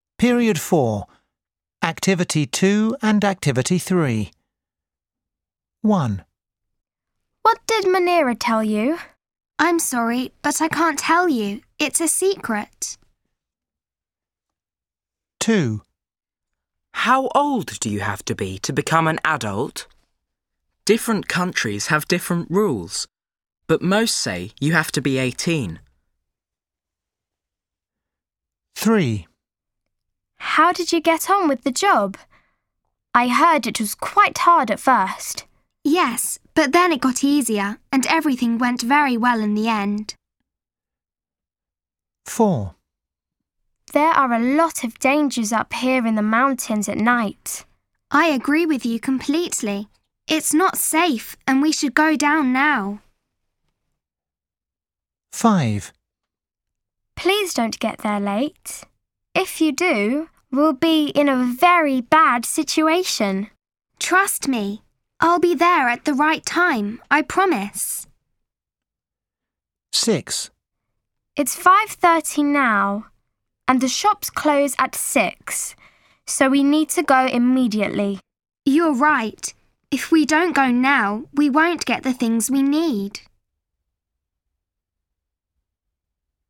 دروس الاستماع